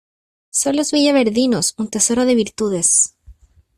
te‧so‧ro
/teˈsoɾo/